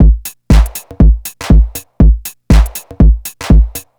NRG 4 On The Floor 025.wav